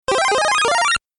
Video Game Sound Effect Notification